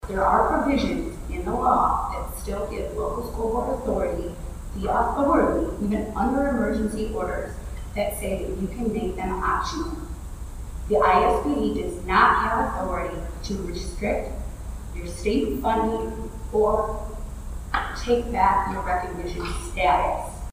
A parent at the Ottawa Elementary School Board meeting Tuesday discussed reasons why kids shouldn’t be forced to wear masks in school.